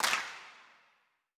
TM88 RelaxClap.wav